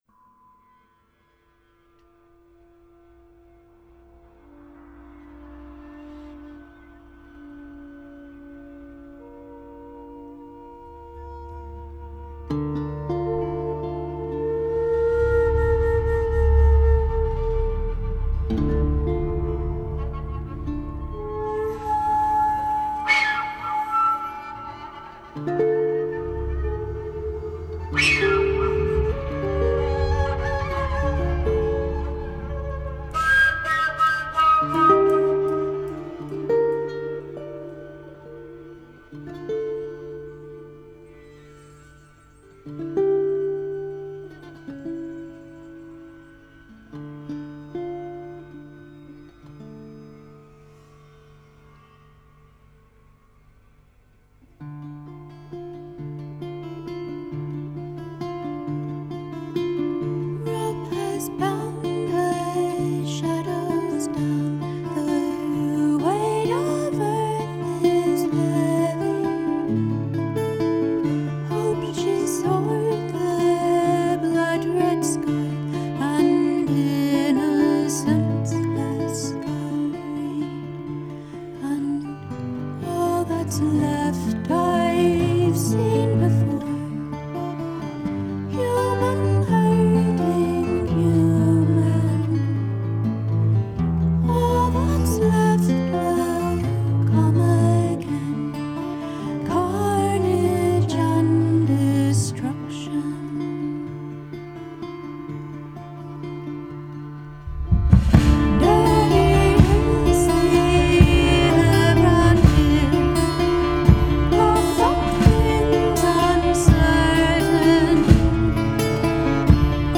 The skinny: Moody folk.